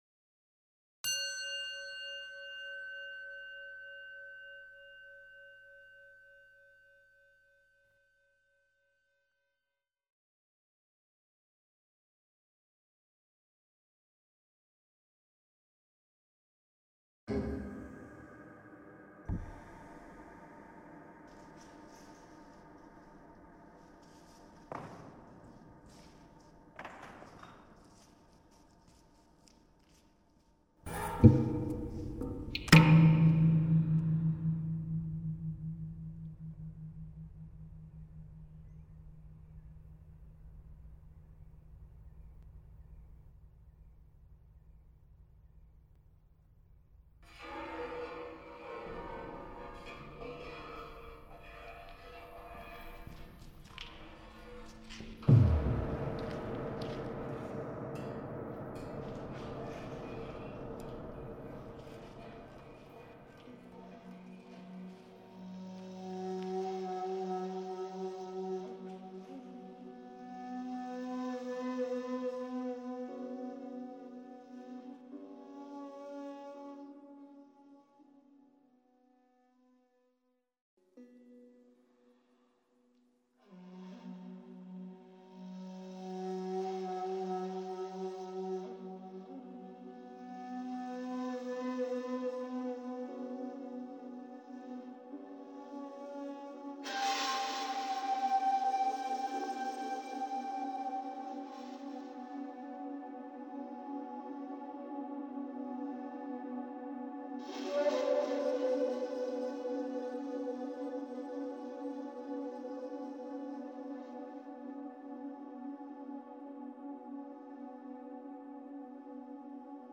various instruments, objects and field recordings.
Here, it is free from distortion and compression.
and outstanding example of reductionist sound cinema.
musique concrète